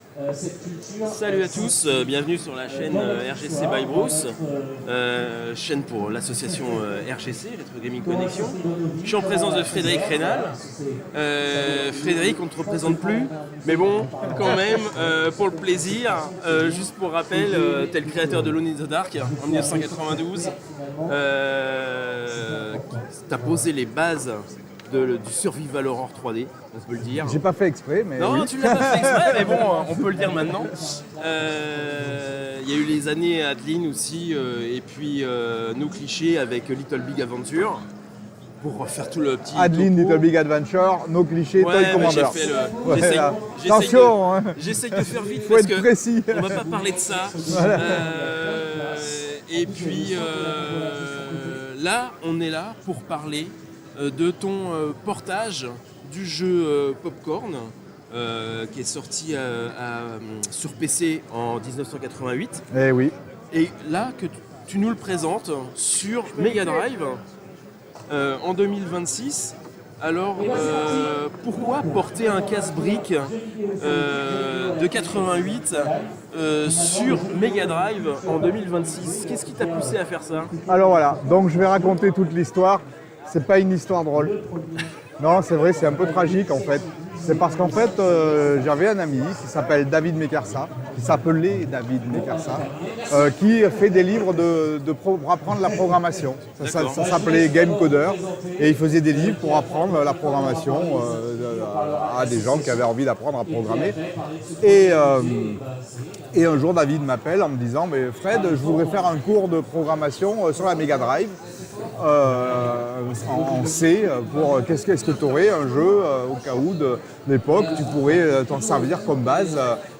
RGPlay 2026 – interview Frédérick Raynal